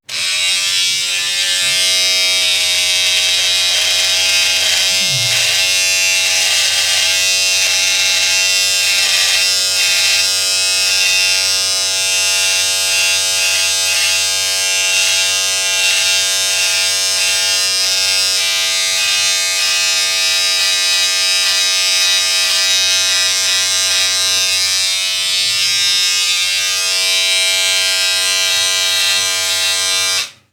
Máquina de afeitar
afeitadora
Sonidos: Acciones humanas
Sonidos: Hogar